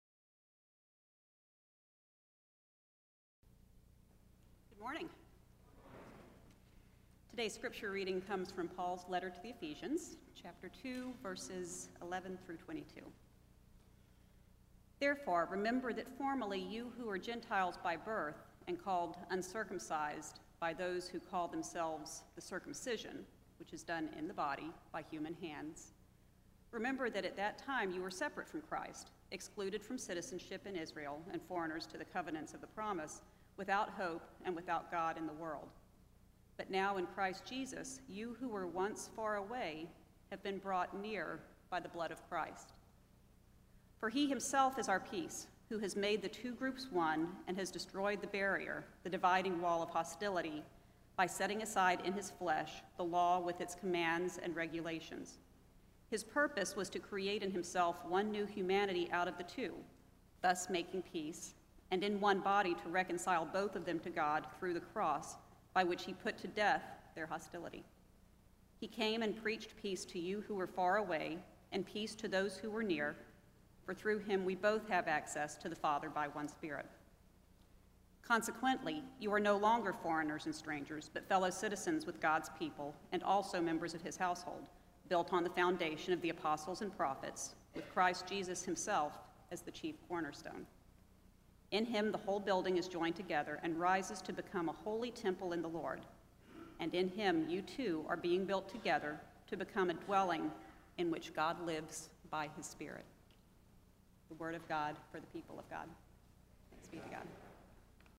audiosermon1-12-14.mp3